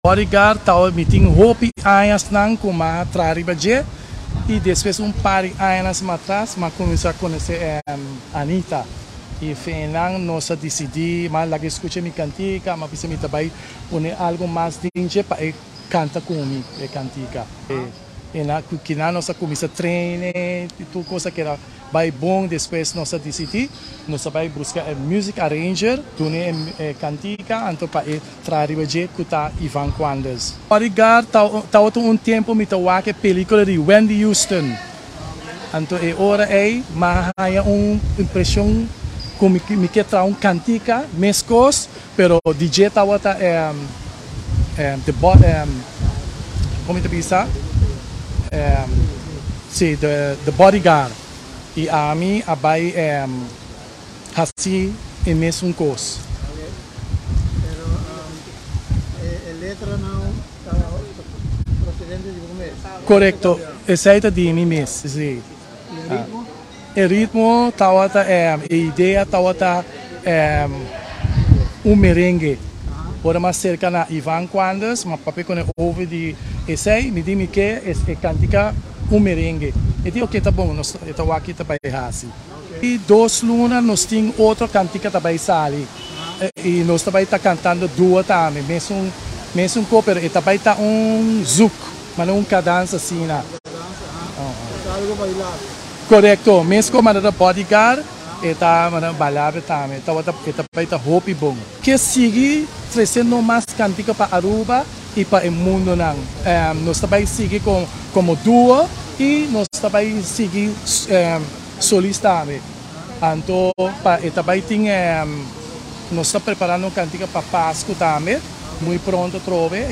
duo
canticanan bailabel